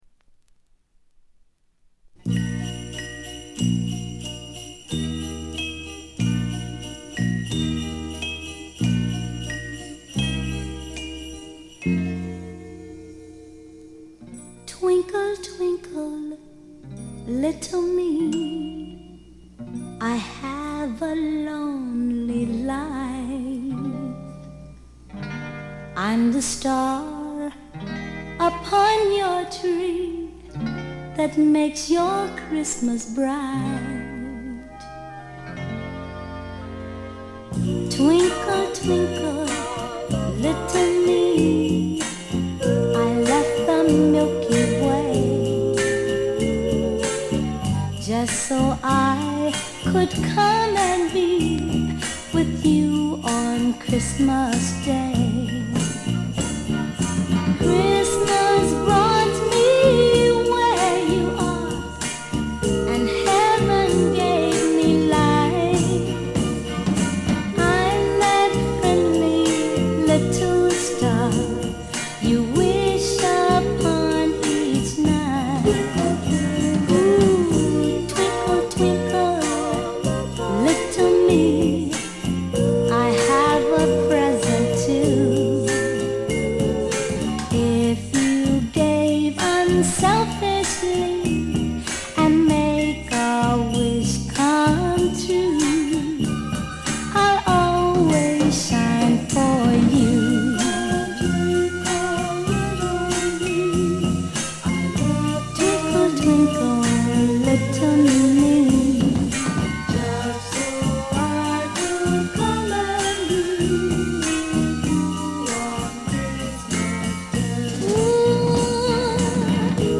ガールポップ基本。
試聴曲は現品からの取り込み音源です。